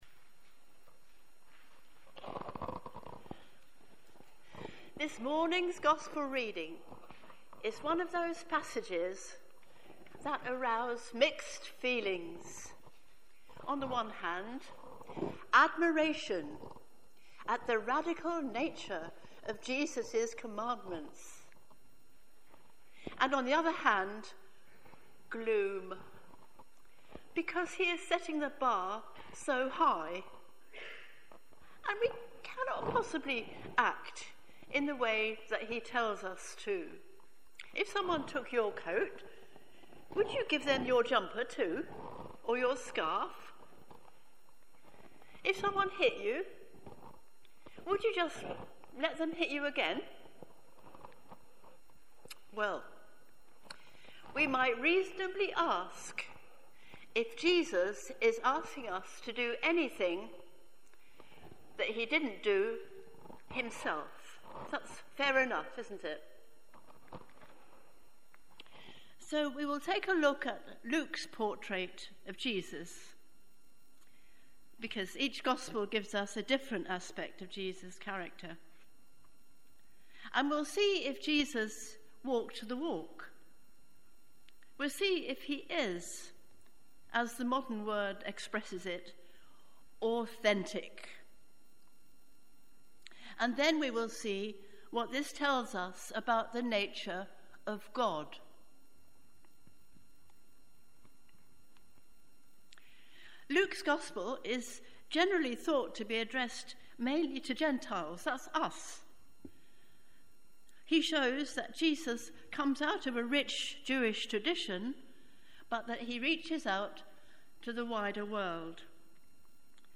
Sermons at LMC
Leatherhead Methodist Church